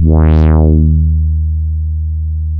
MG MOD.D#2 1.wav